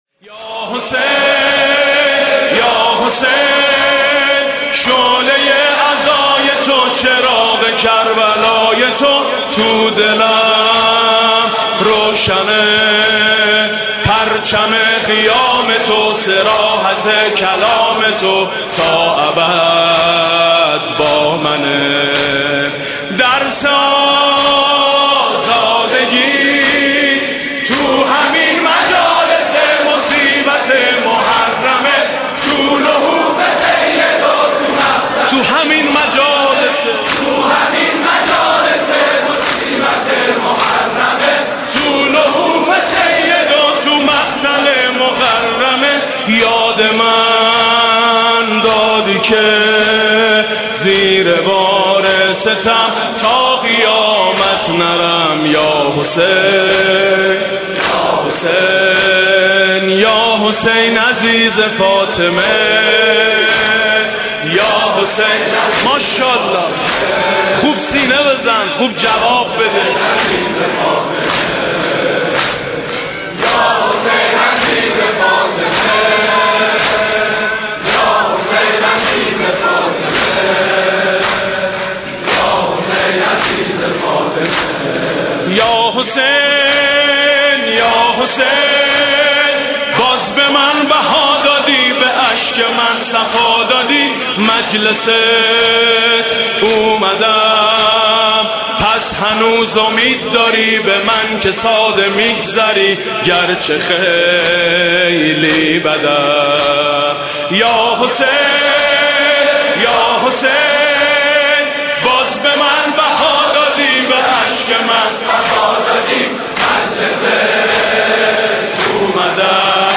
«محرم 1396» (شب پنجم) دم پایانی: یا حسین یا حسین، من کجا لیاقت اقامه عزای تو؟